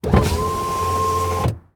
windowpart1.ogg